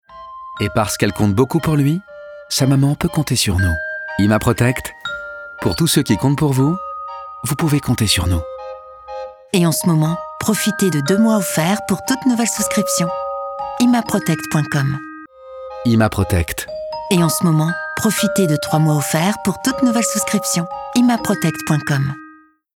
douce